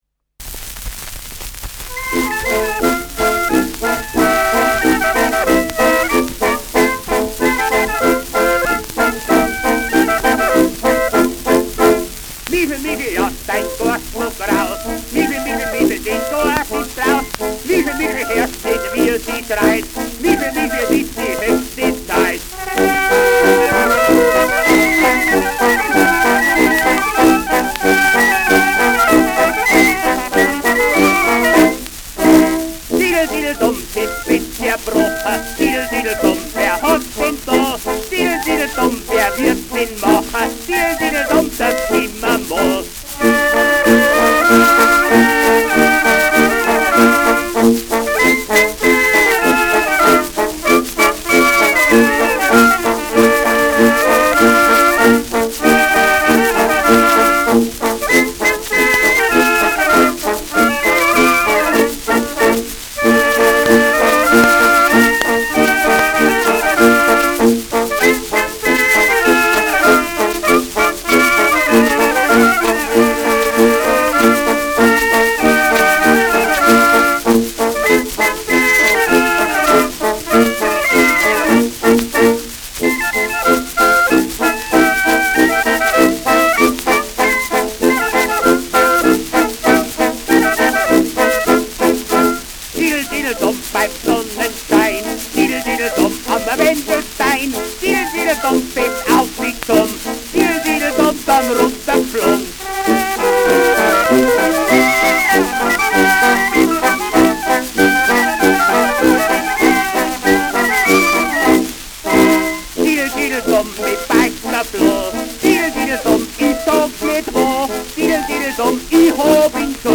Schellackplatte
präsentes Rauschen : präsentes Knistern : gelegentliches Knacken : leichtes Leiern : präsentes Nadelgeräusch
Dachauer Bauernkapelle (Interpretation)
[München] (Aufnahmeort)